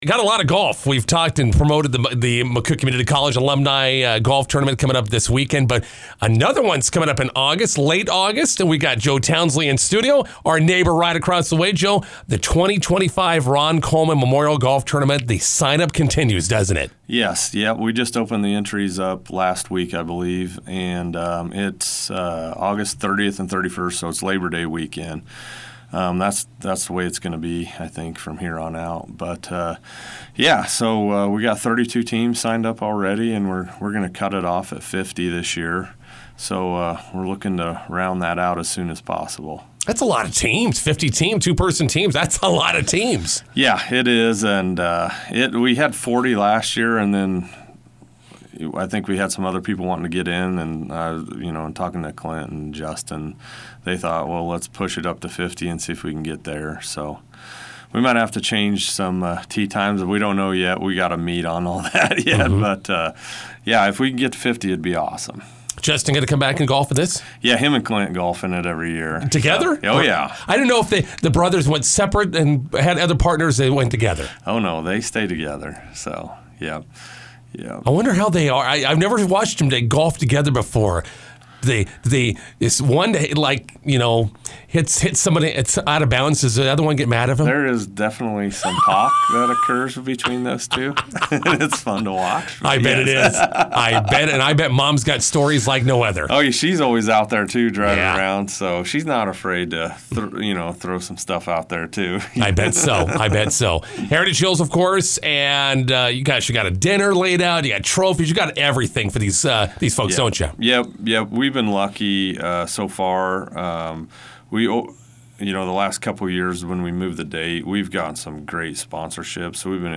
INTERVIEW: Sign-up continues for the Ron Coleman Memorial Golf Tournament in August.